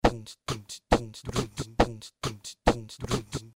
伦理恍惚的鼓声 Var 2
描述：Trance drums Var 2.电动电子恍惚节拍
Tag: 140 bpm Trance Loops Drum Loops 590.79 KB wav Key : Unknown